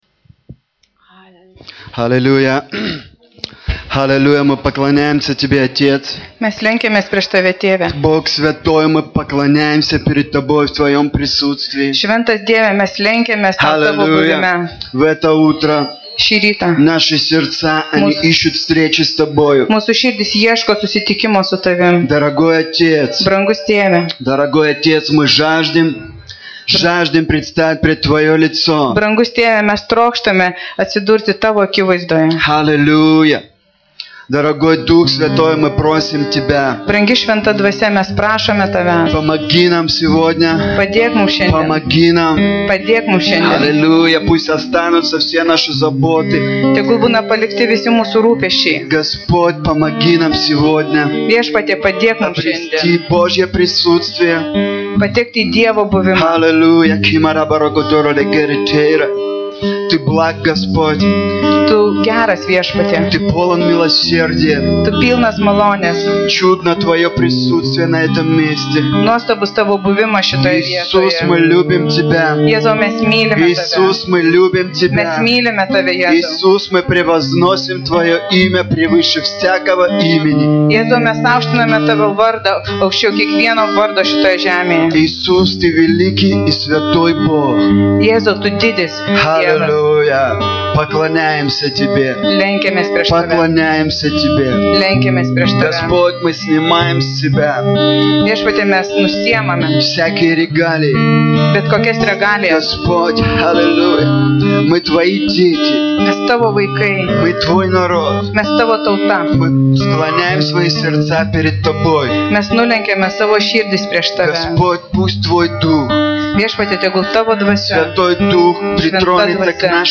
Pamokslai